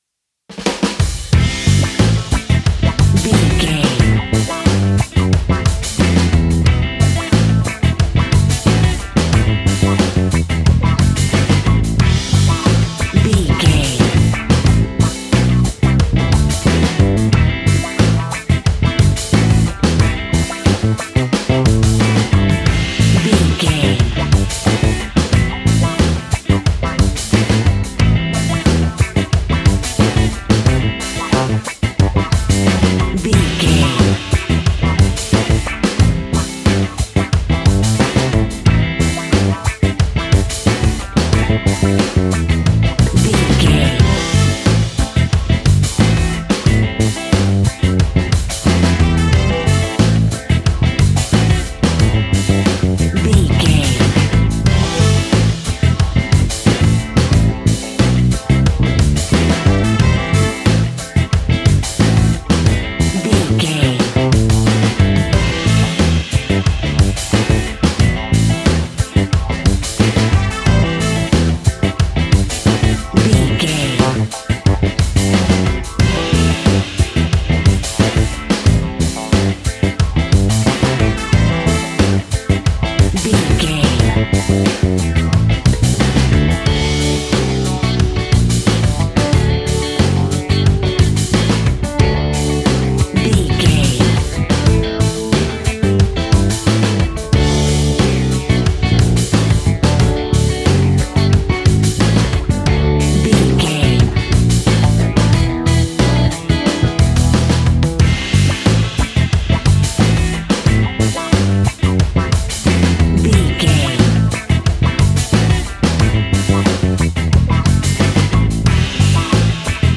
Aeolian/Minor
groovy
lively
electric guitar
electric organ
drums
bass guitar
saxophone